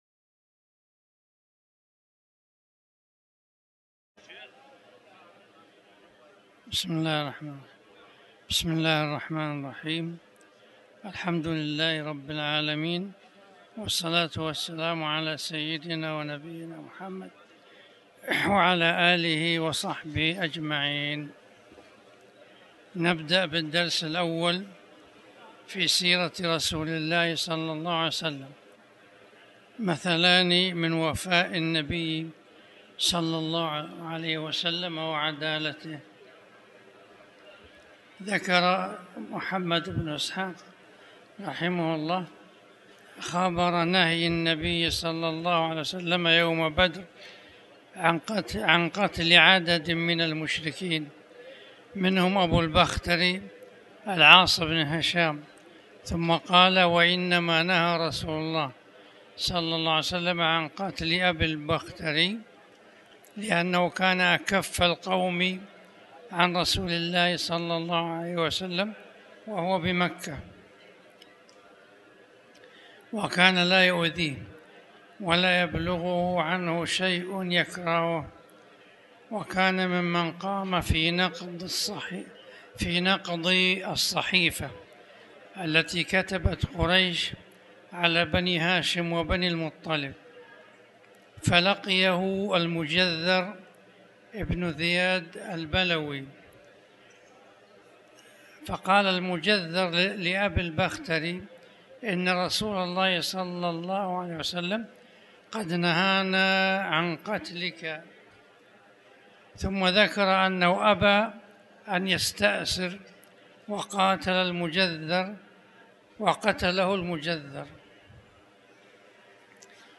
تاريخ النشر ٢٣ ربيع الثاني ١٤٤٠ هـ المكان: المسجد الحرام الشيخ